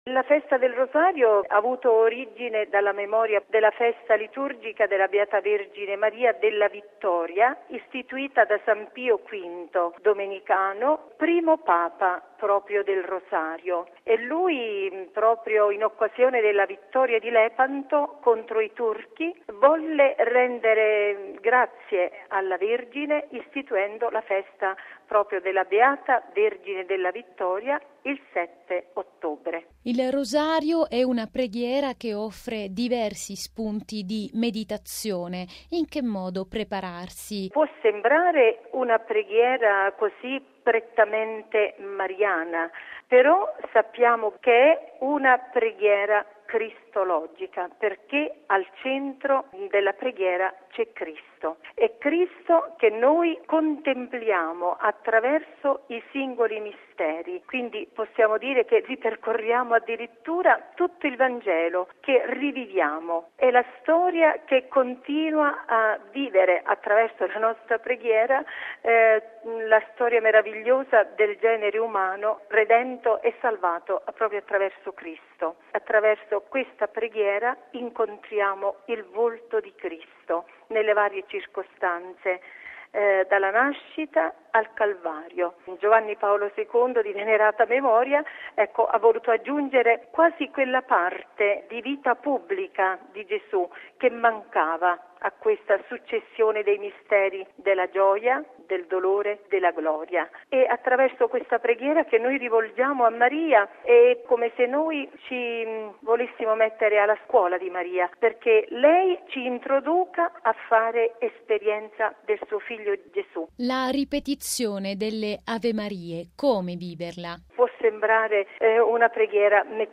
Al microfono